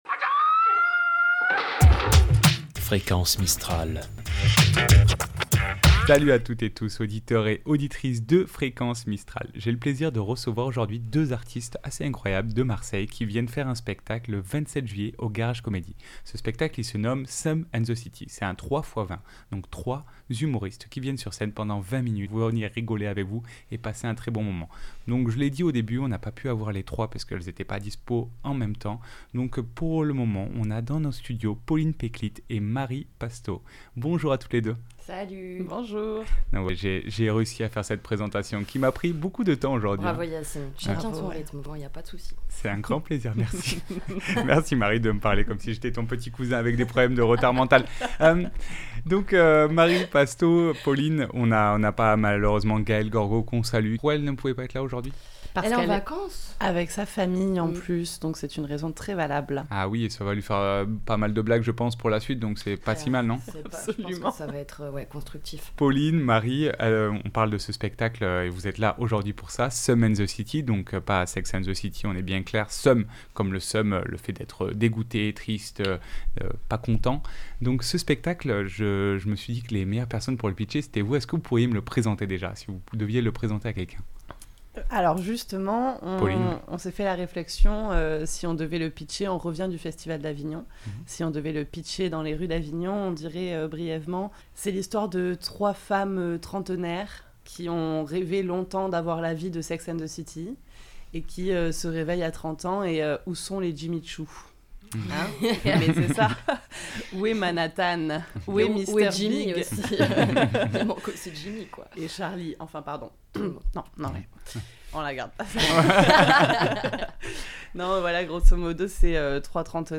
Seum and the city Itw.mp3 (29.37 Mo)